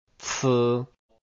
字母名稱的讀法是按注音符號的注音，不同於英文的讀法。
ㄘㄝ